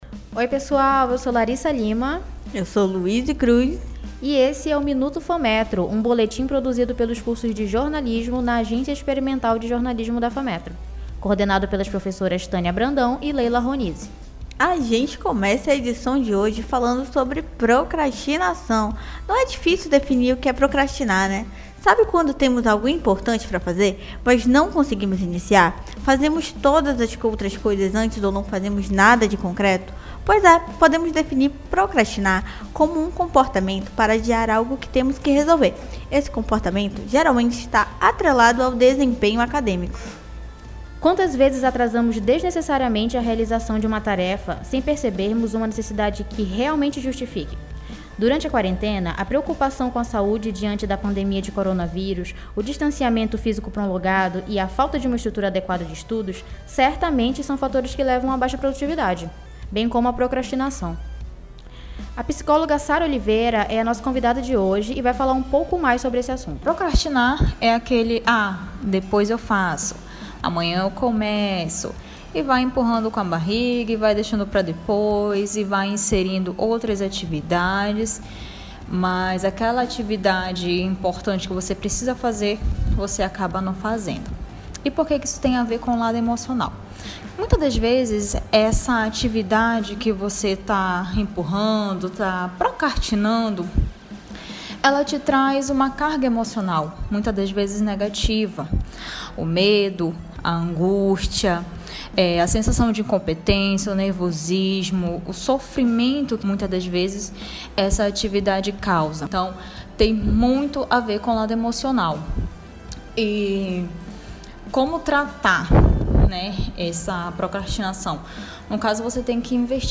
Conversamos com uma psicóloga que explicou as causas e passou dicas de como contornar a situação, além da dica cultural, um filmaço que acabou de chegar aos cinemas, confira agora.
boletim-radio-MINUTO-FAMETRO.mp3